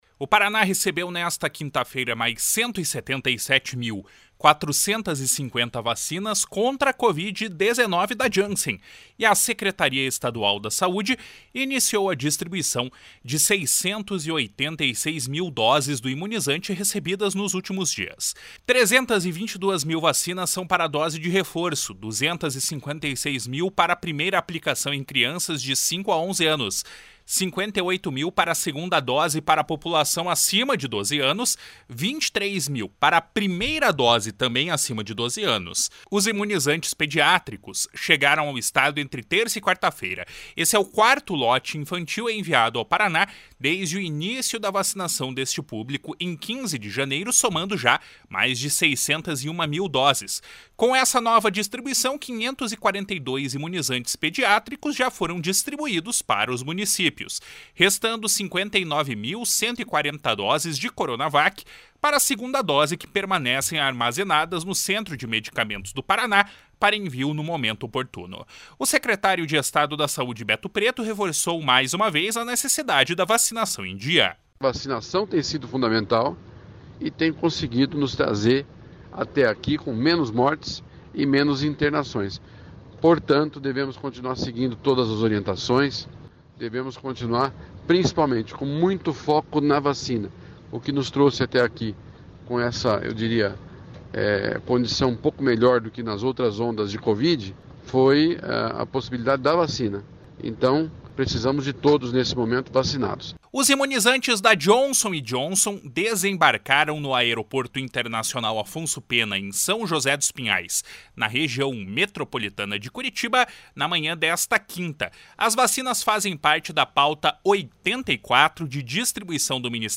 O secretário de Estado da Saúde, Beto Preto, reforçou mais uma vez a necessidade da vacinação em dia. // SONORA BETO PRETO //